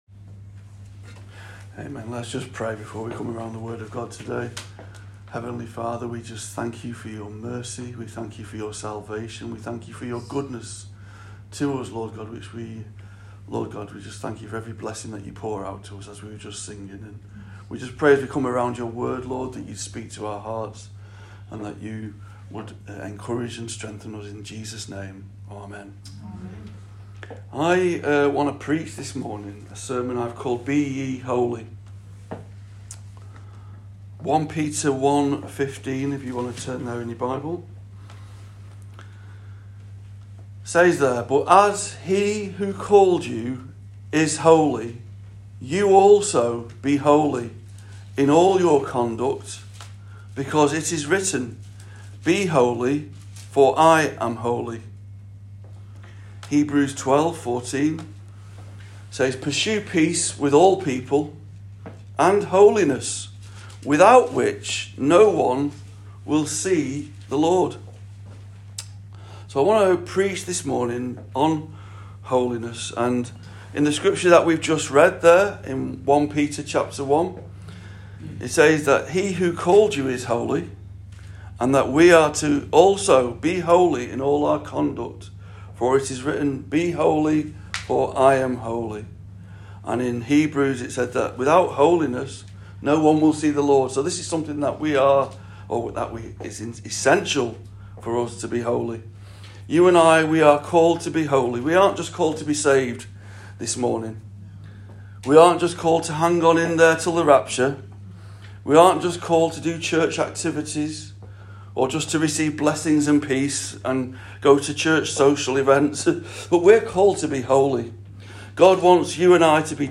SERMON “BE YE HOLY”